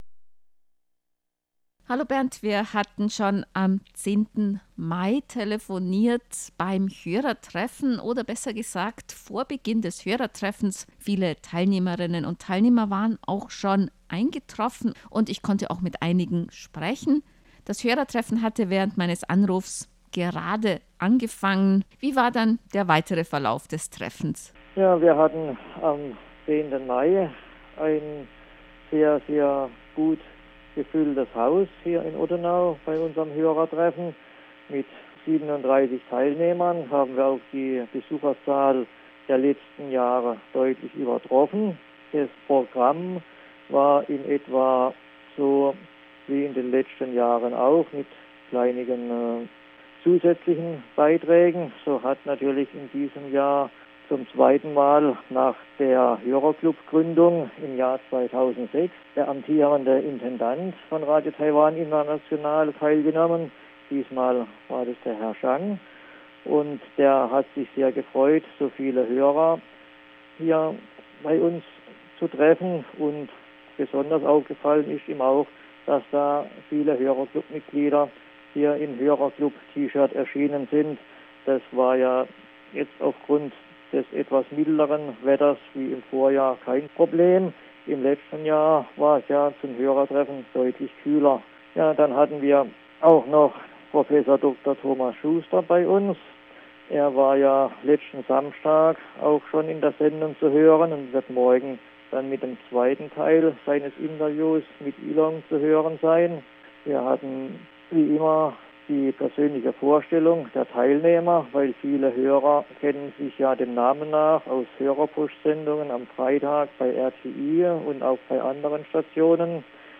33. �berregionales DX-Treffen des Ottenauer Kurzwellenh�rerklubs Murgtal und RTI H�rerklubs Ottenau am 10. Mai 2014